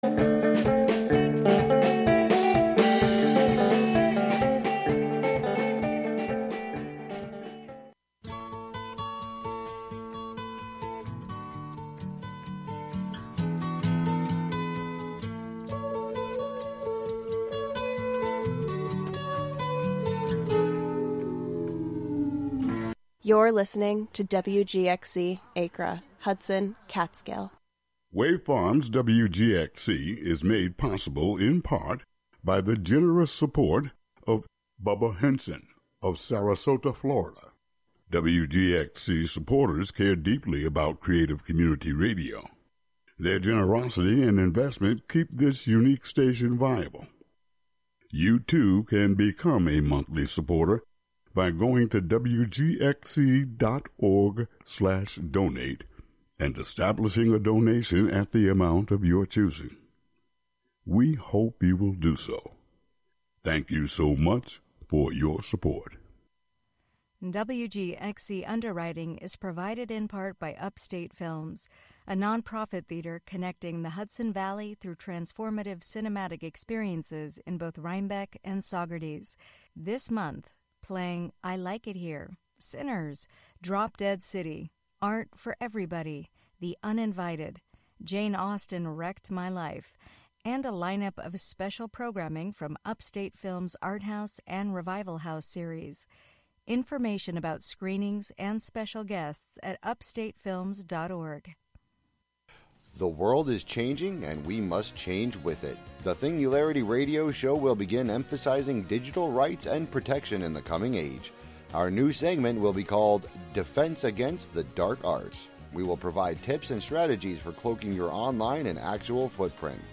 In-studio / pedestrian interviews, local event listings, call-ins, live music, and other chance connections will be sought on air.